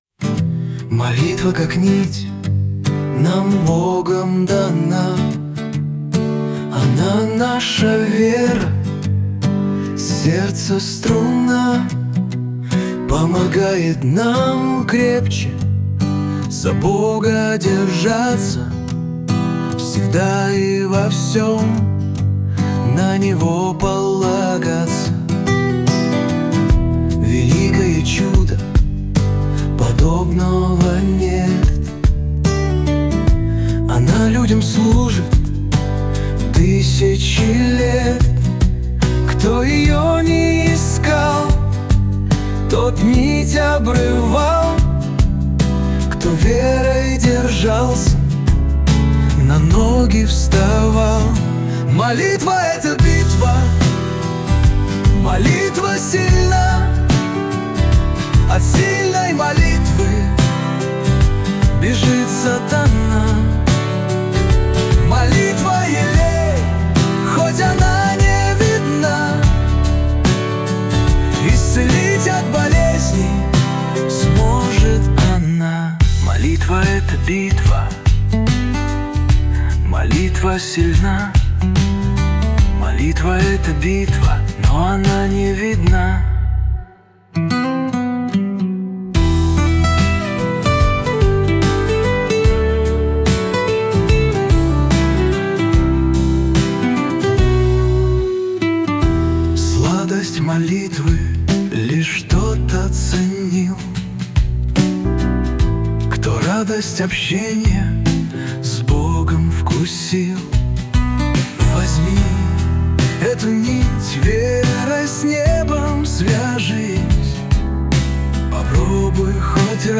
песня ai
740 просмотров 2017 прослушиваний 151 скачиваний BPM: 73